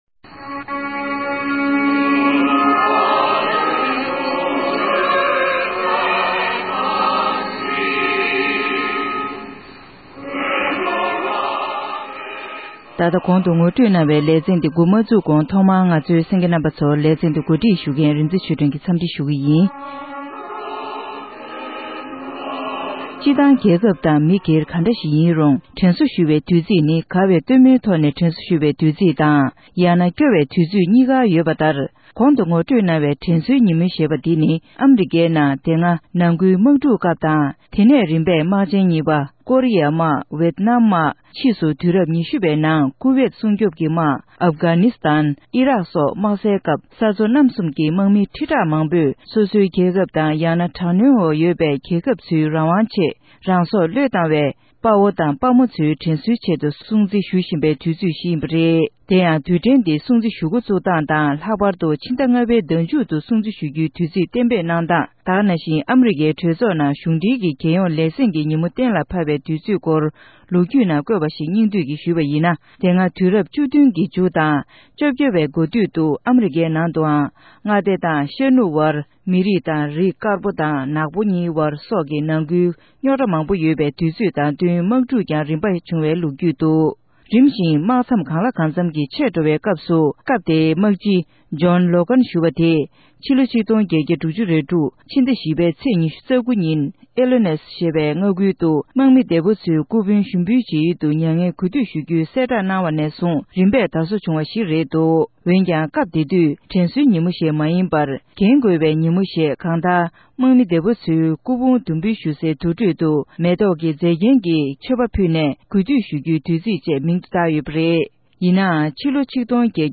སྒྲ་ལྡན་གསར་འགྱུར།